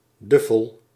Duffel (Dutch pronunciation: [ˈdʏfəl]
Nl-Duffel.ogg.mp3